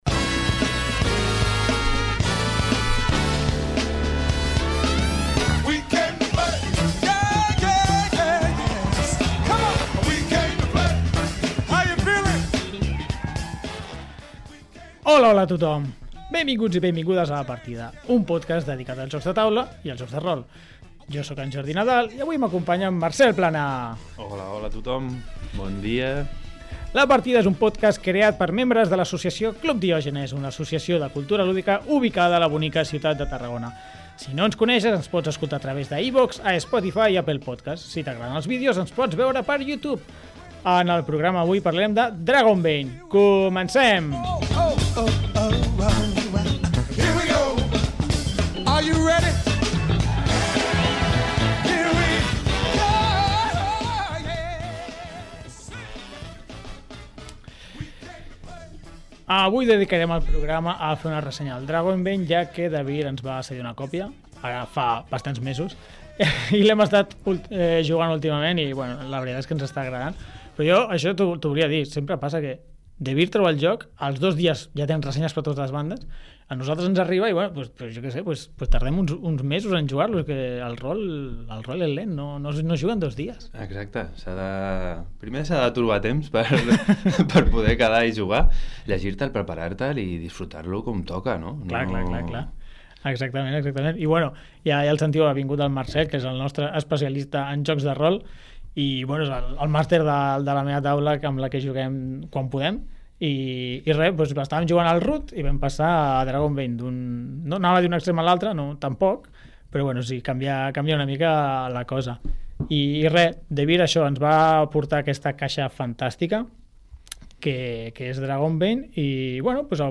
El col·lectiu Diògenes ens porta “La Partida”, el programa on coneixerem diferents jocs de taula amb la participació d’experts i aficionats a l’oci alternatiu.